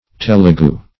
telegu - definition of telegu - synonyms, pronunciation, spelling from Free Dictionary Search Result for " telegu" : The Collaborative International Dictionary of English v.0.48: Telegu \Tel`e*gu"\, prop. a. & n. same as Telugu .